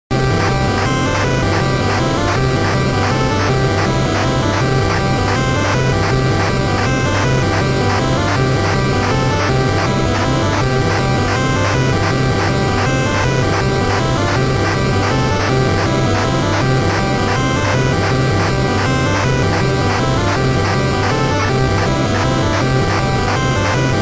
chiptuneぜえはあ
一言でまとめると、ファミコンのぴこぴこした音が好きでたまらん。
♪１（適当に押し込んだノイズがめっちゃうるさいので注意）
矩形波２つと三角波とノイズともうひとつDCPMってのがあるんですが、最後のはよく分かりません。